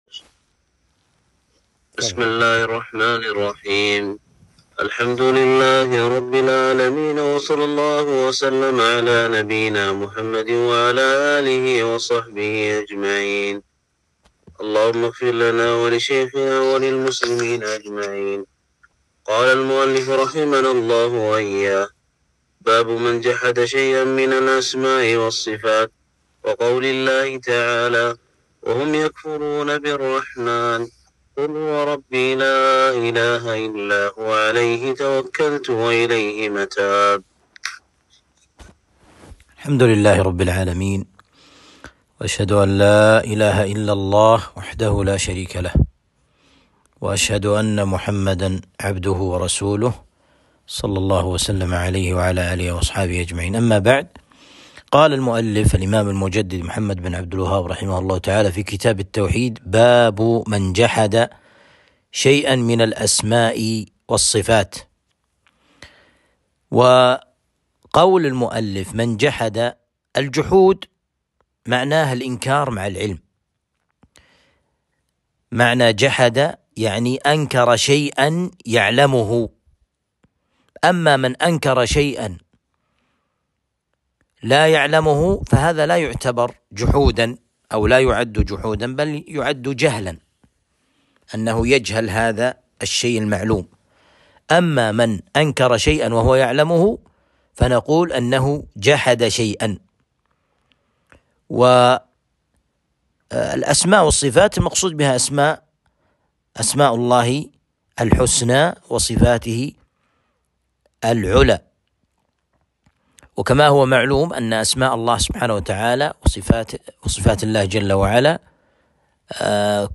درس شرح كتاب التوحيد (٤٠)